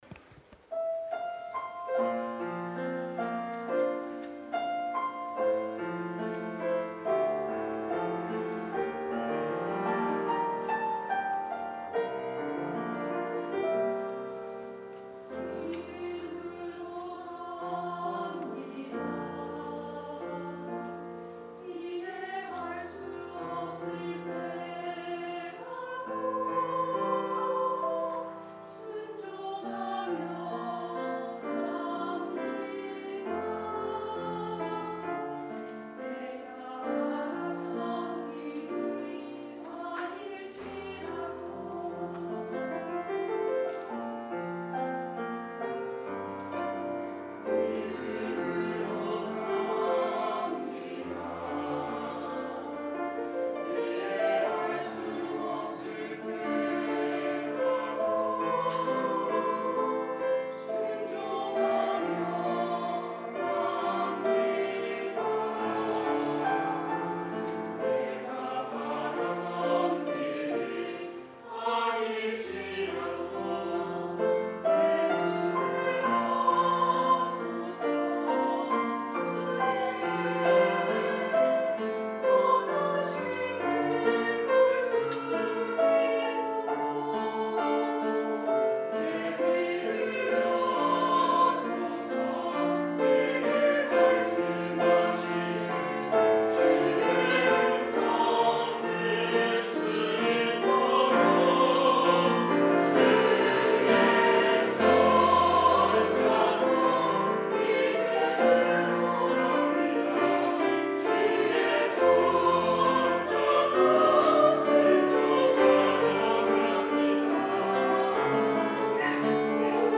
주일찬양